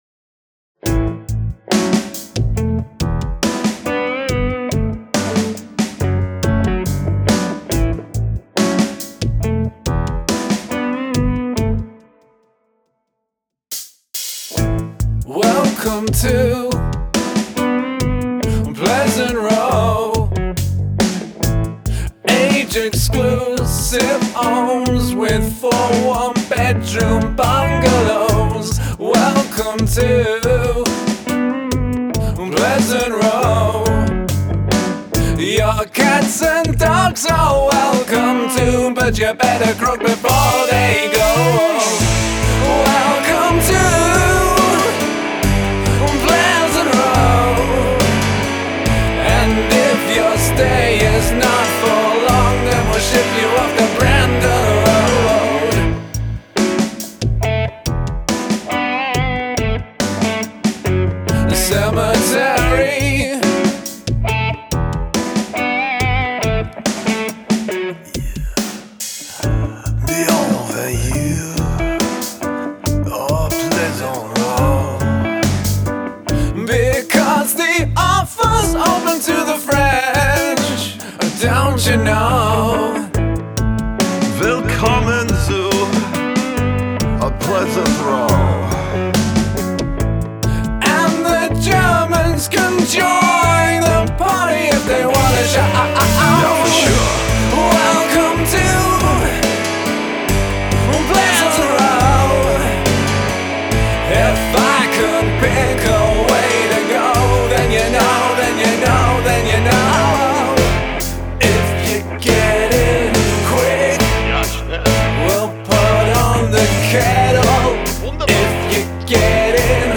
The vocal phrasing and tone are good.